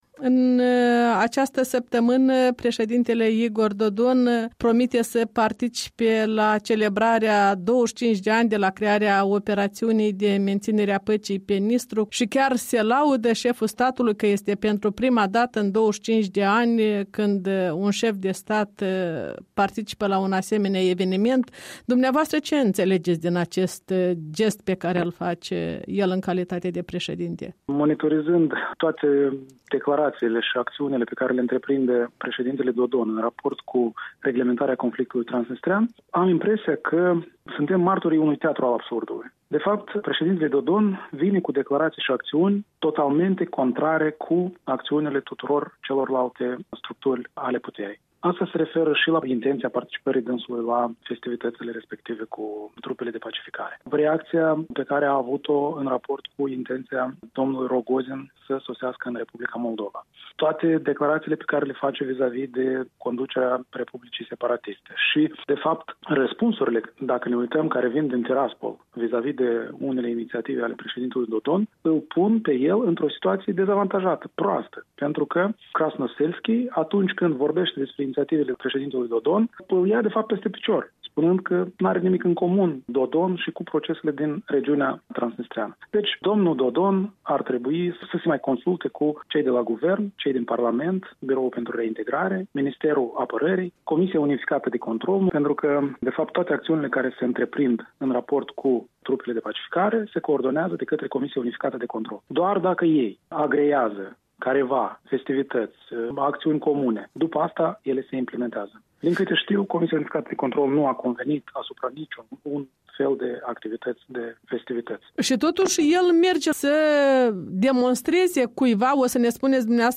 Un interviu cu Eugen Carpov, fostul vicepremier responsabil cu Reintregrarea